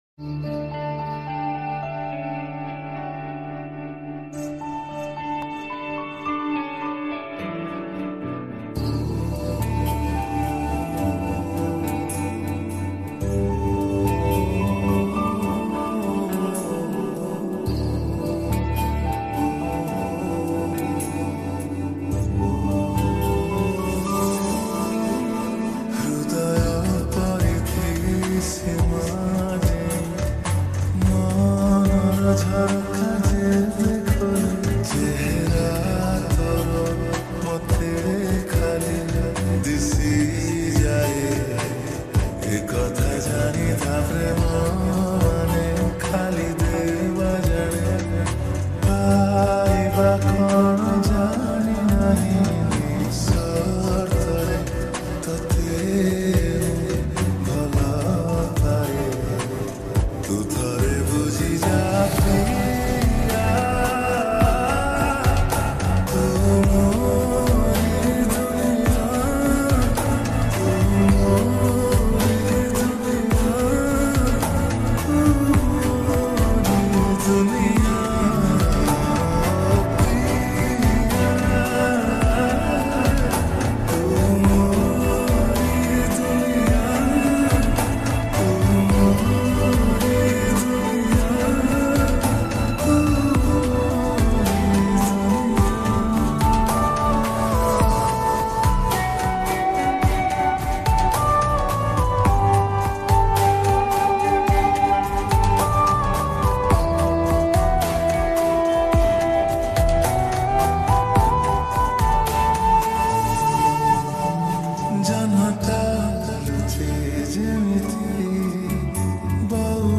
odia lofi song